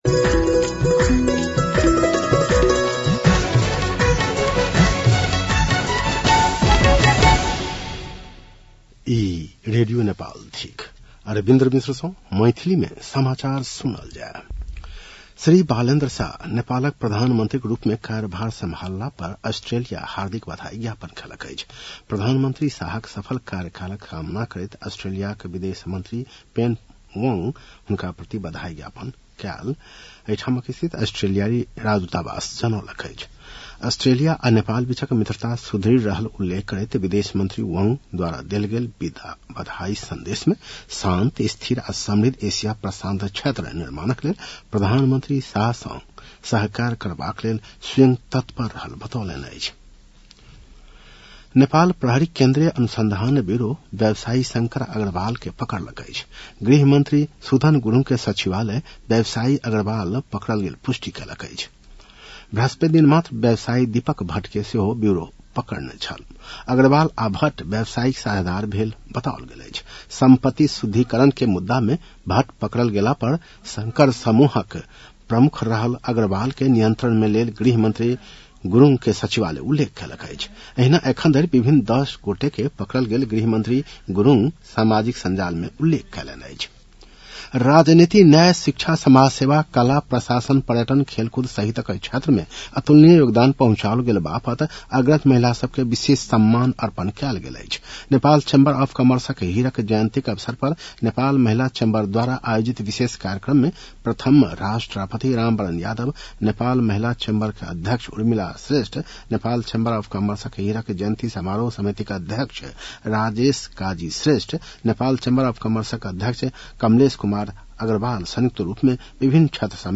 मैथिली भाषामा समाचार : २१ चैत , २०८२